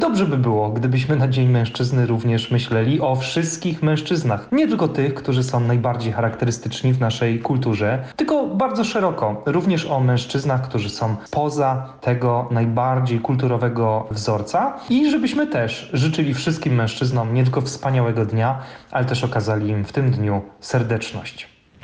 trener mentalny.